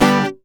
OVATION G-.1.wav